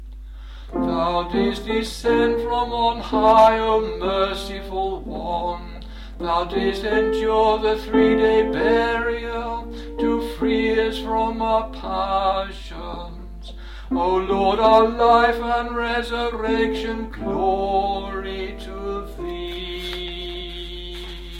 TONE 8 TROPARION
tone-8-troparion.mp3